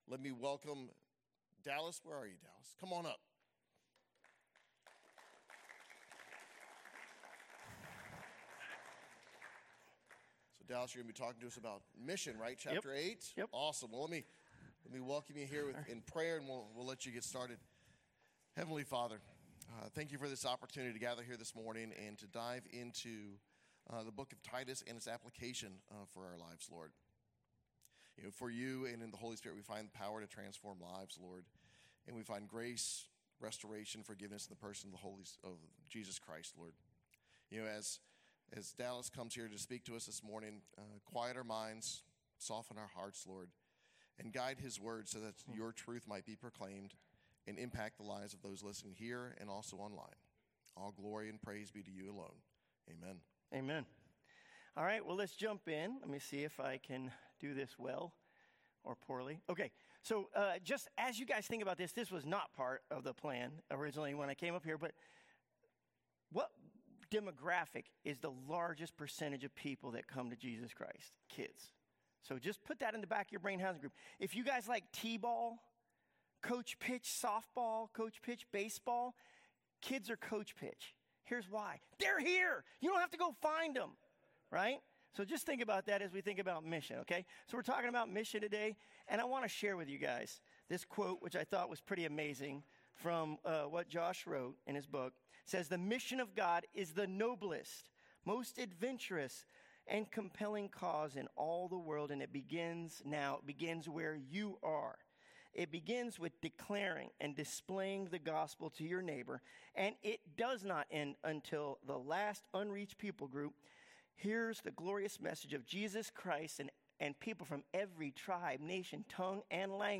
2025 Burke Community Church Lesson